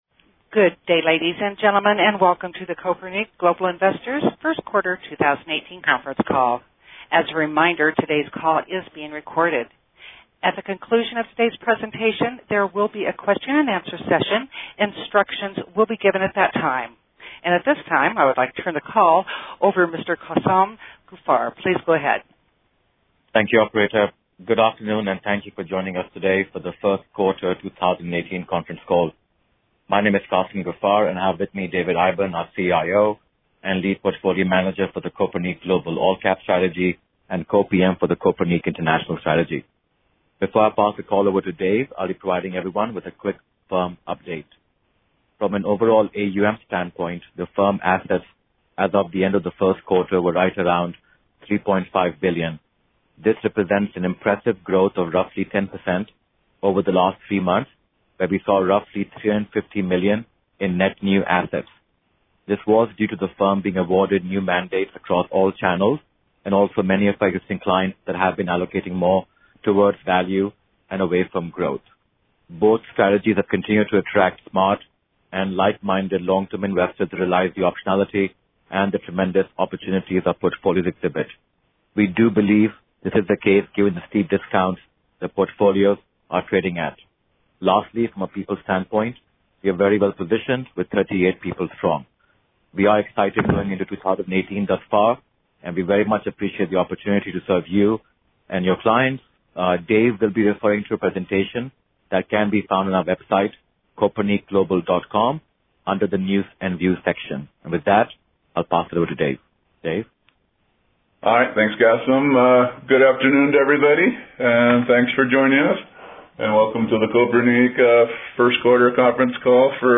Q1 2018 Conference Call - Kopernik Global Investors